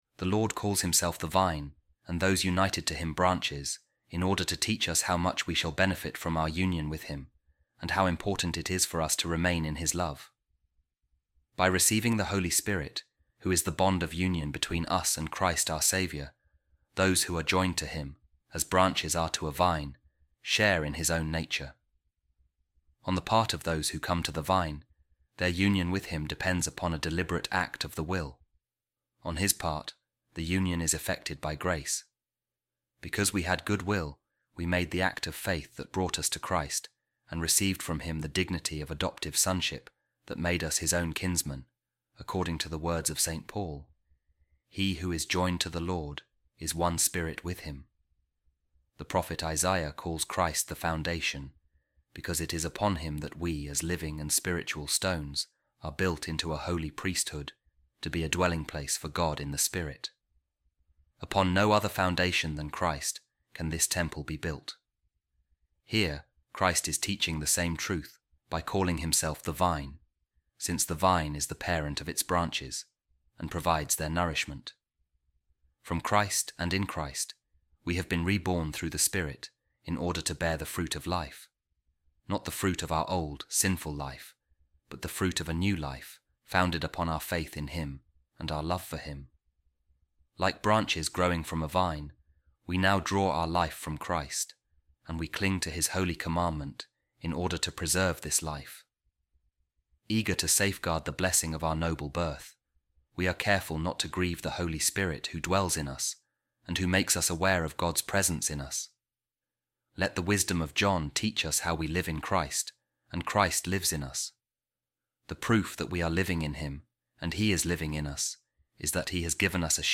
A Reading From The Commentary Of Saint Cyril Of Alexandria On Saint John’s Gospel